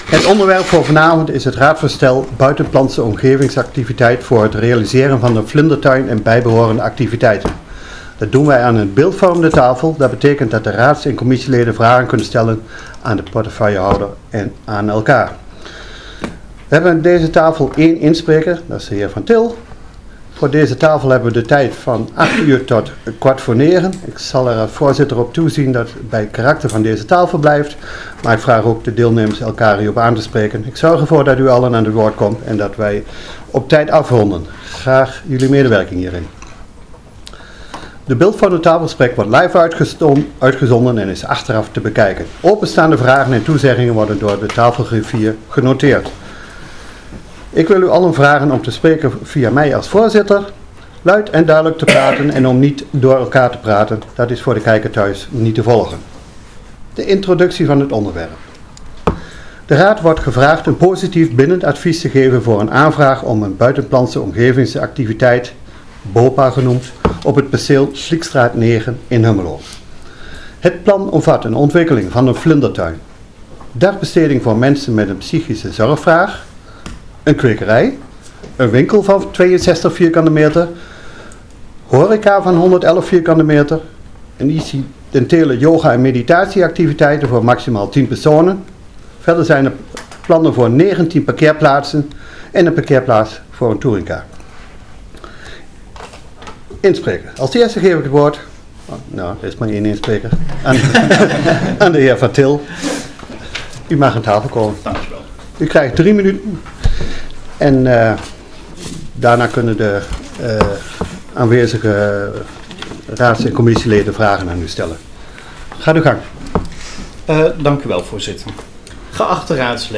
Je krijgt 3 minuten de tijd om je verhaal te vertellen over het onderwerp wat op dat moment wordt besproken. Daarna mogen de raadsleden vragen aan jou stellen, aan de betreffende portefeuillehouder en aan elkaar.
De tafel wordt ook LIVE uitgezonden.